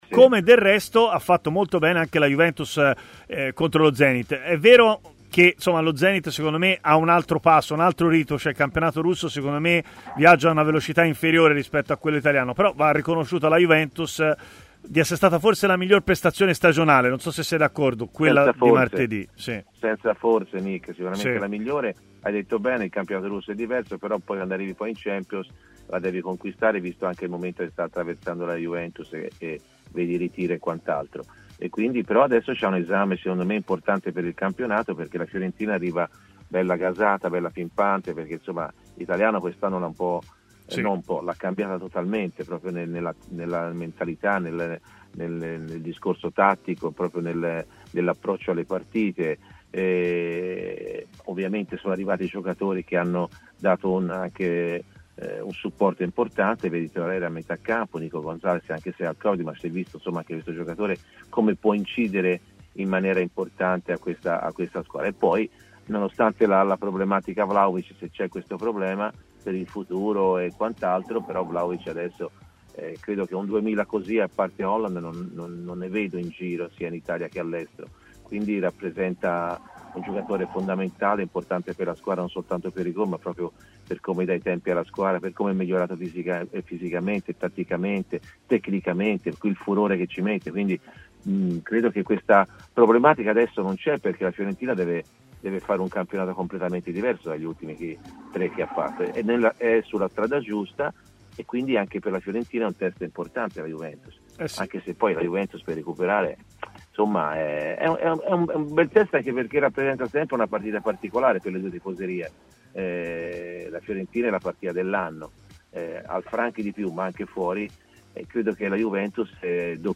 Nel suo consueto intervento del giovedì a Stadio Aperto su TMW RadioAntonio Di Gennaro ha parlato della Fiorentina.